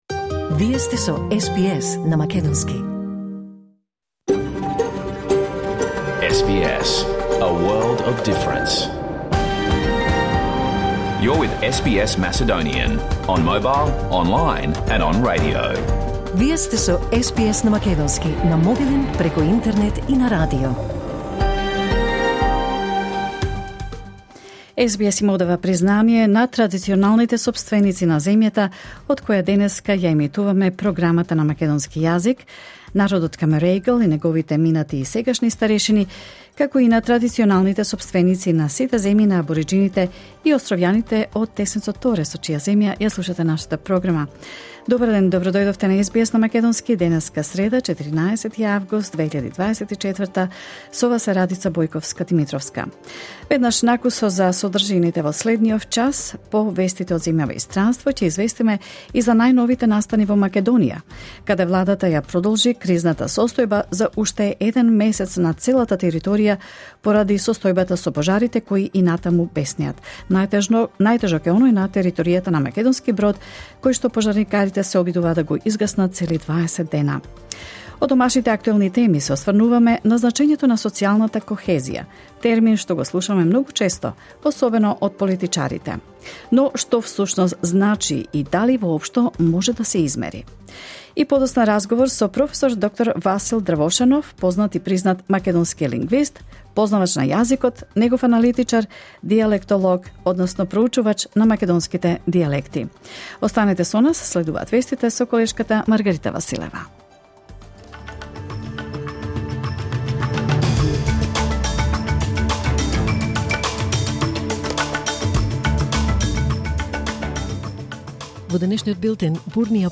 SBS Macedonian Program Live on Air 14 August 2024